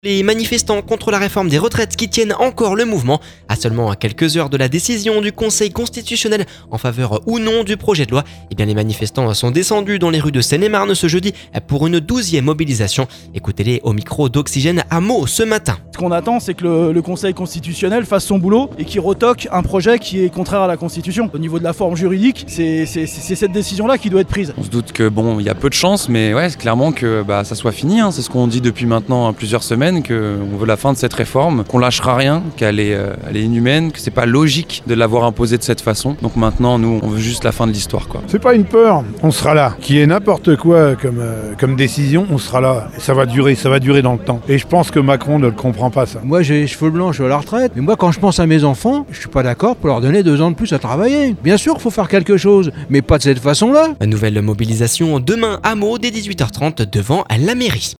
A seulement quelques heures de la décision du Conseil Constitutionnel en faveur ou non du projet de loi, les manifestants sont déscendus dans les rues de Seine-et-Marne ce jeudi pour une douzième mobilisation. Ecoutez-les au micro d’Oxygène à Meaux ce matin…